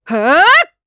Glenwys-Vox_Casting4_jp.wav